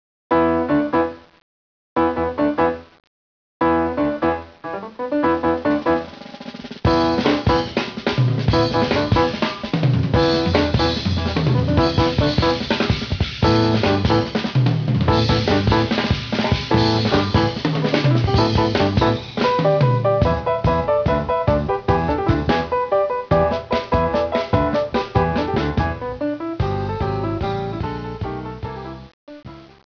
It proved to be a fortuitous move, for there he met two musicians he immediately recognized could become the nucleus of an exciting jazz trio.
inventive 16 string bass solo
forceful drum solo turn this version into an exciting blend of East and West.